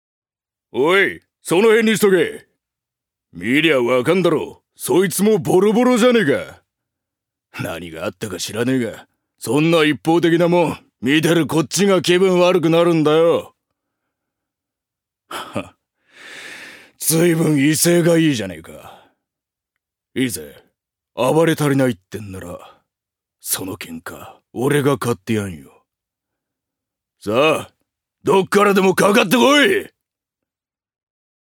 所属：男性タレント
セリフ２